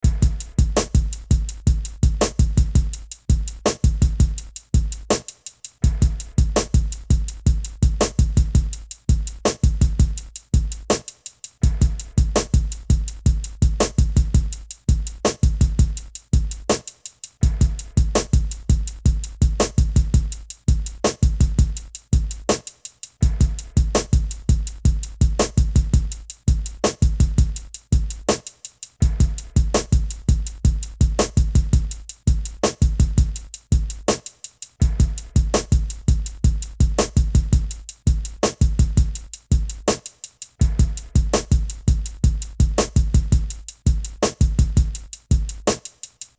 Here is the beat looped for 8 times: